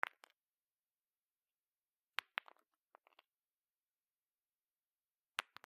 石 転がす
/ H｜バトル・武器・破壊 / H-50 ｜土・石
『コ コロ』